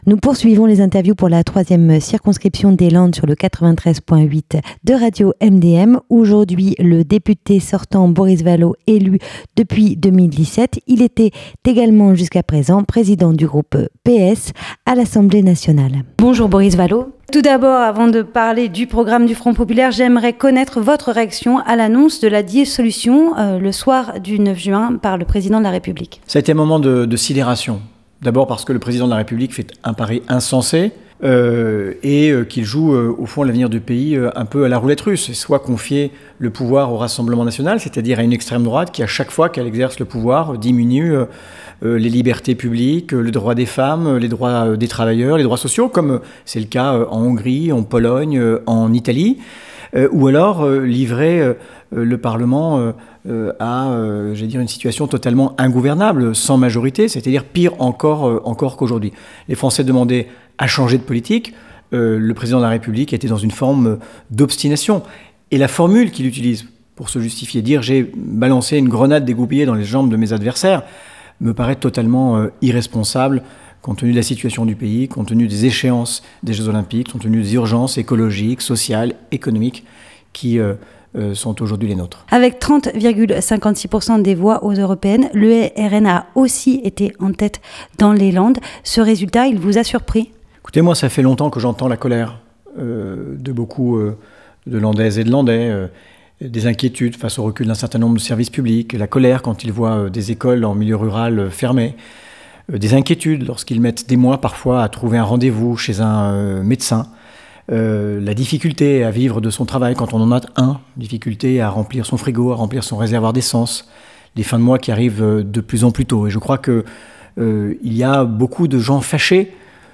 Le député sortant Boris Vallaud élu depuis 2017, il était également jusqu’à présent président du groupe P.S. à l’Assemblée nationale : voici son interview.
ITV-Boris Vallaud.mp3